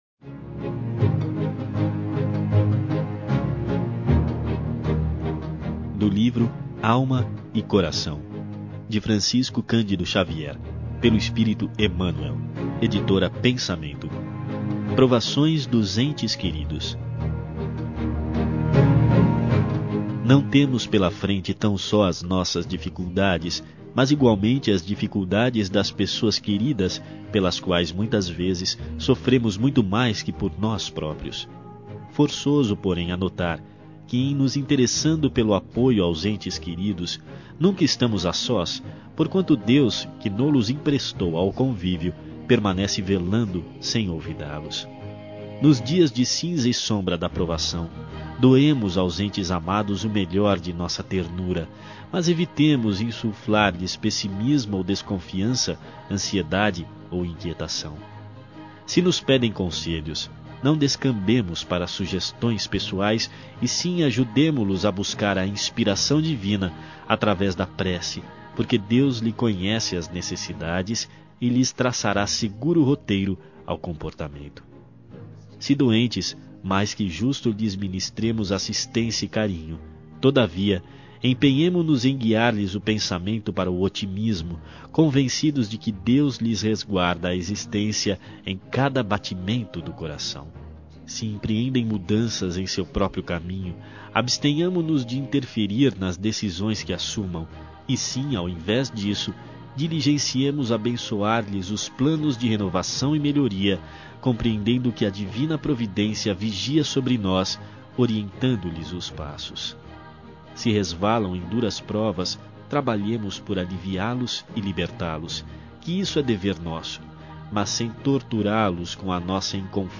Ouça outras mensagens na voz de Chico Xavier Clicando aqui